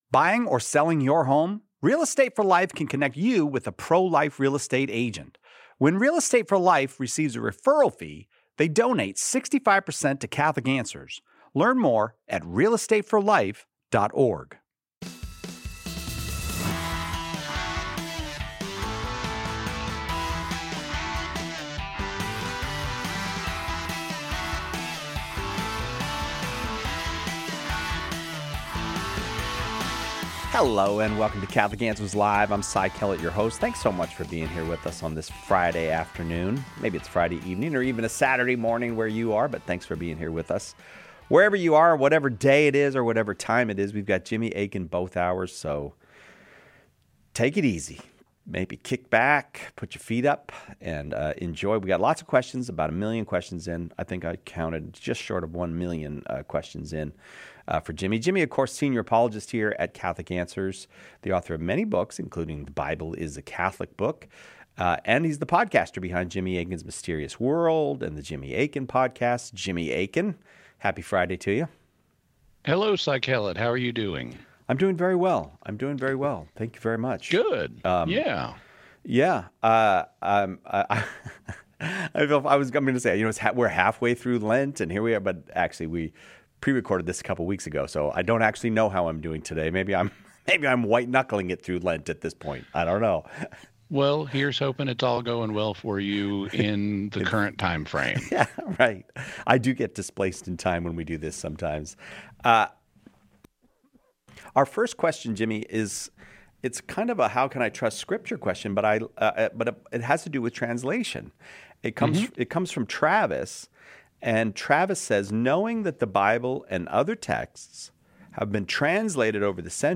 In this episode of Catholic Answers Live , Catholic Answers apologists address a thoughtful concern about biblical translation and interpretation, explaining how the Church safeguards authentic teaching across centuries.
A wide-ranging and intellectually engaging conversation on faith, reason, and modern questions.